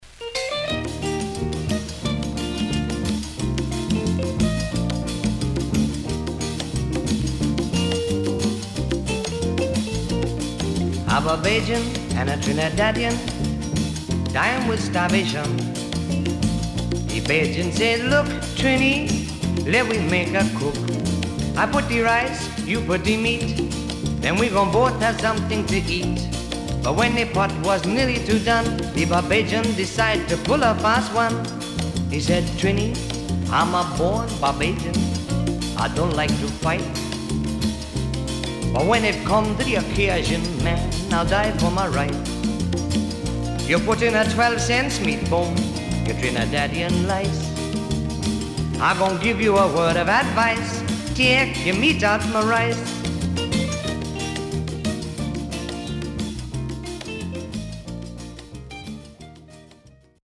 SIDE B:少しノイズあり、曲によってヒスが入りますが良好です。